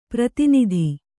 ♪ prati nidhi